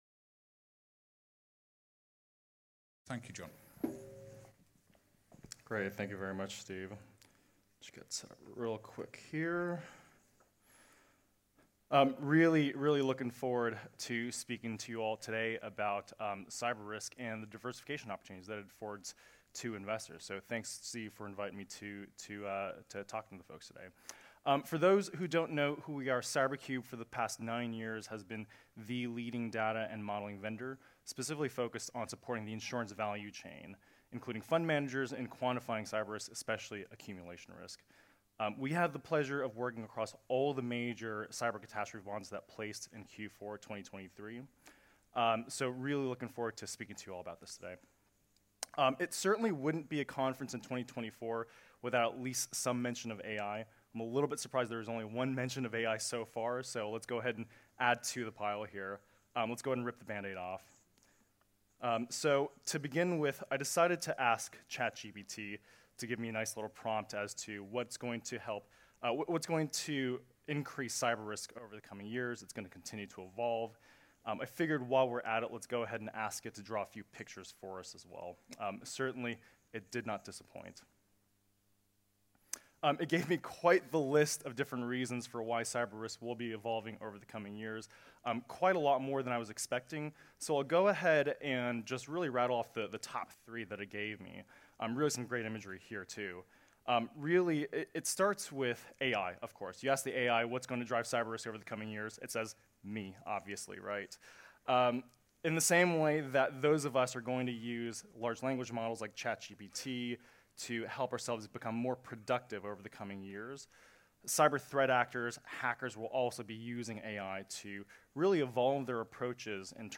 This episode features the second session of the day at Artemis' ILS Asia 2024 conference, which was held in Singapore on July 11th. It was our sixth in-person conference in Singapore focused on catastrophe bonds, insurance-linked securities (ILS) and alternative reinsurance capital trends. The second session of the day was a keynote speech and fireside chat interview focused on cyber catastrophe bonds.